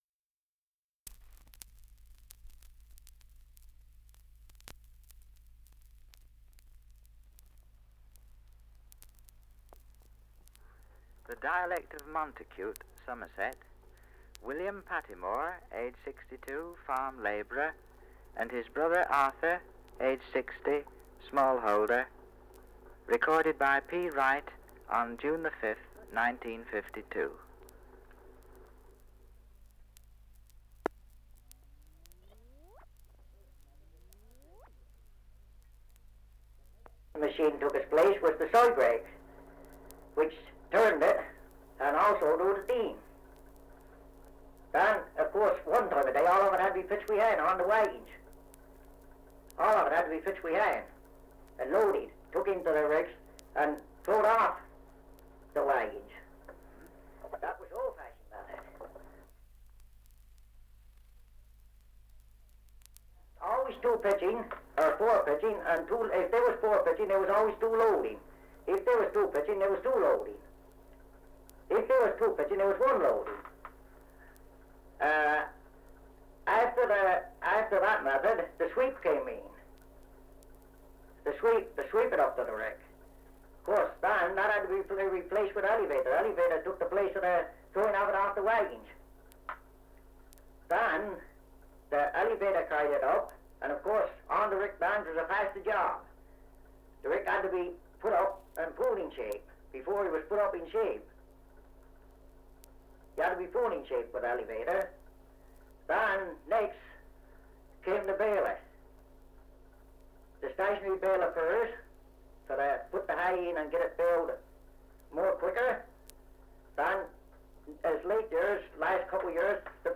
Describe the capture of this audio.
Title: Dialect recording in Montacute, Somerset 78 r.p.m., cellulose nitrate on aluminium